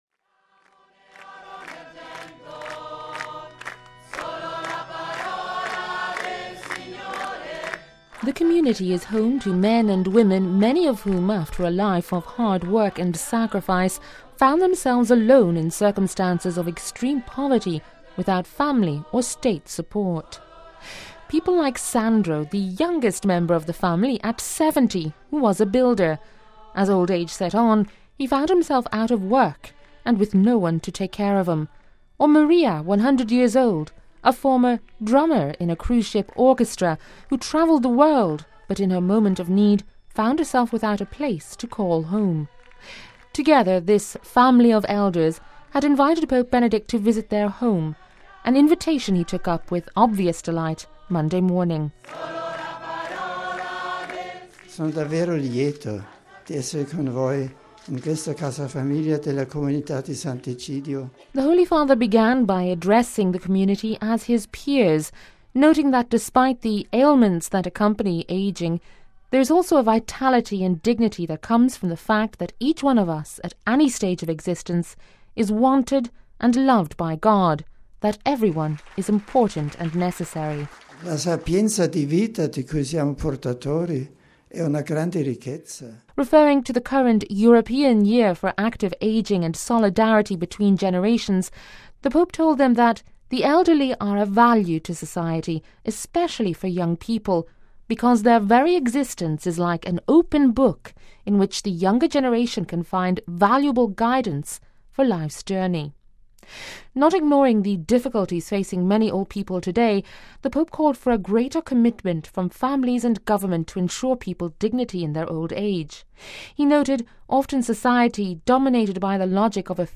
(Vatican Radio) “The quality of a society, I would say of a civilization, is judged by how well older people are treated and the place reserved for them in community life. Whoever makes room for the elderly makes room for life! Whoever welcomes the elderly welcomes life! ", affirmed Pope Benedict XVI during a visit Monday morning to the Community of Sant'Egidio home for the elderly on the Janiculum Hill, Rome.